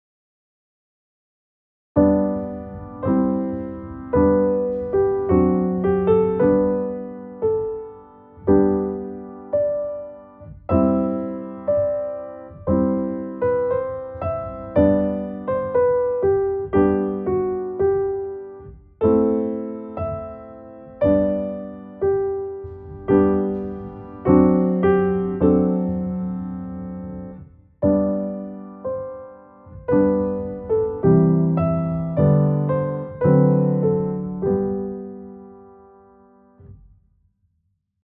このコードを付けがちだけど、これではちょっと物足りないな、という例を示しておきます。